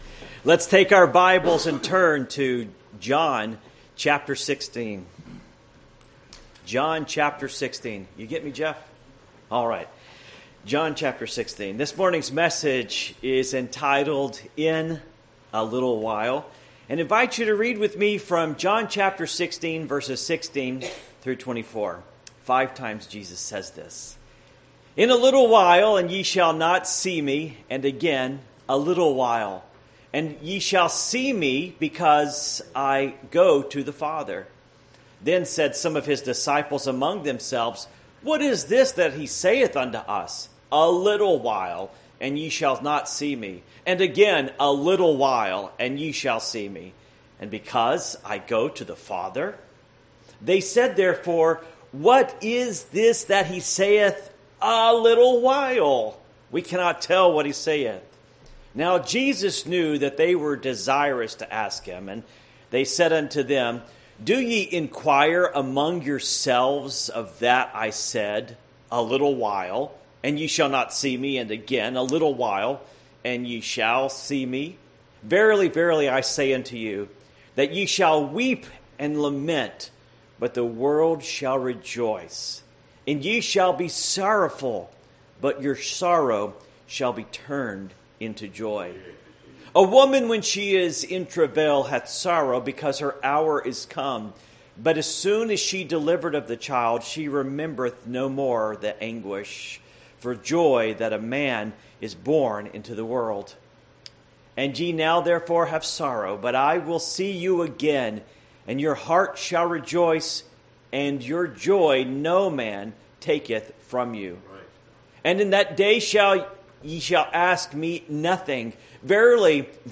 Passage: John 16:16-24 Service Type: Morning Worship